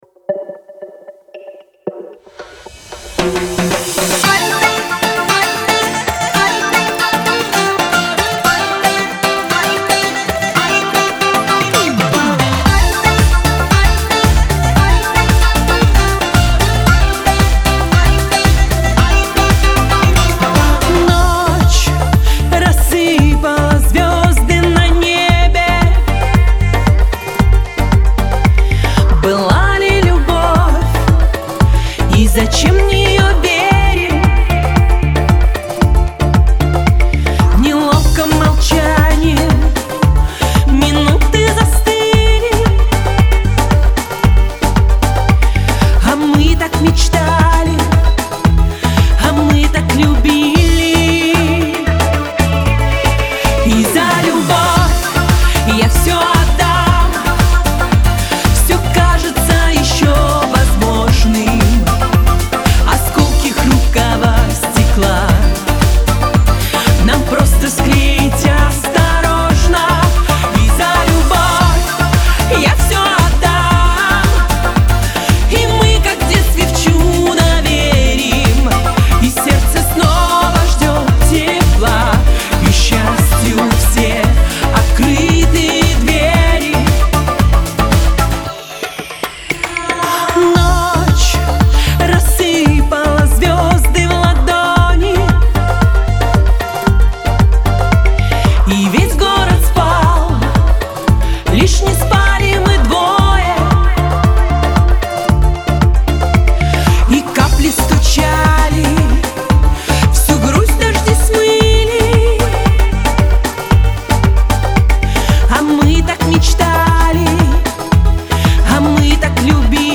Романтический весенний настрой настиг и Варвару.